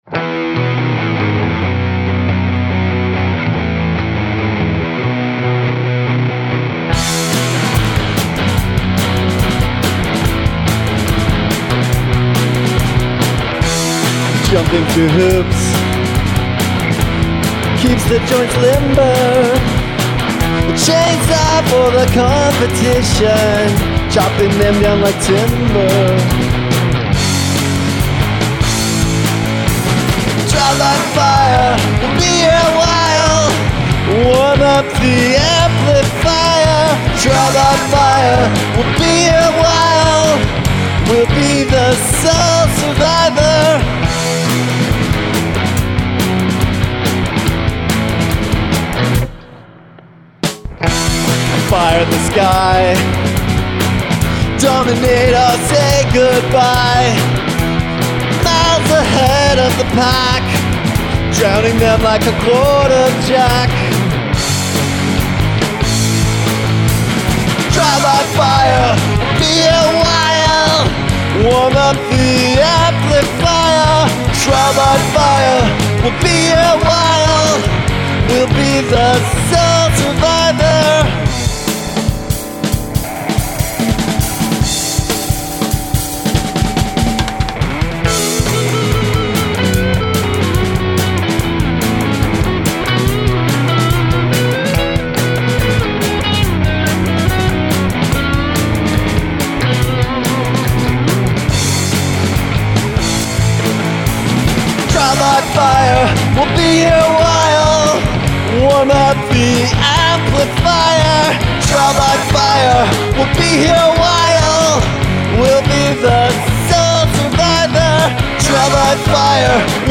Incorporate sirens